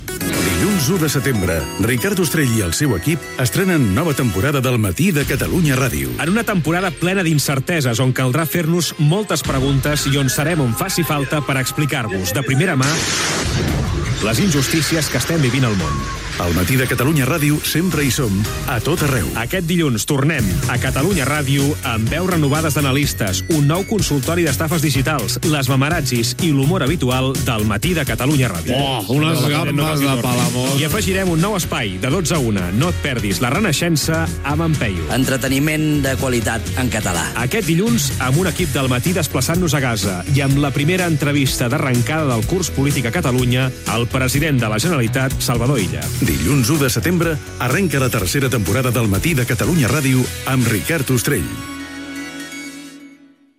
Promoció del programa en l'inici de la temporada 2025-2026
Info-entreteniment